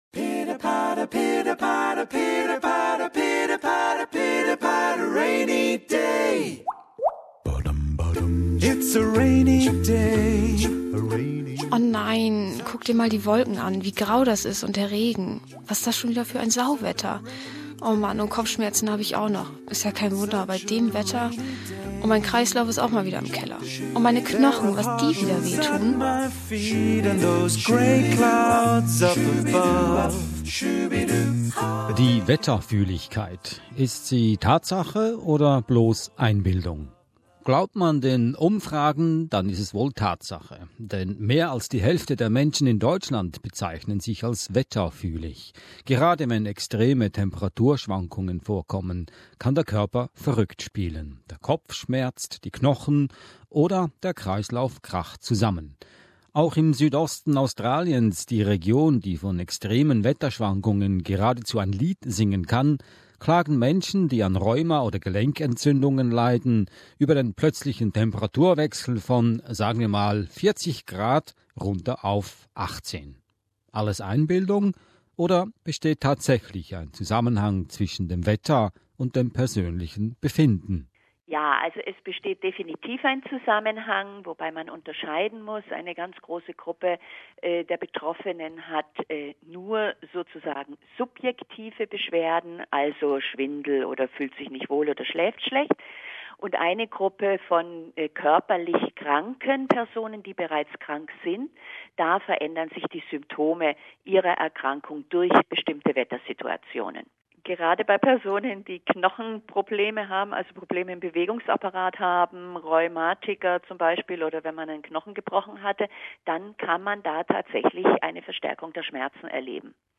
Wie weit ist die Wetterfühligkeit Tatsache oder Einbildung? Eine Medizinerin und medizinische Klimatologin, eine Psychotherapeutin und ein Meteorologe geben Auskunft.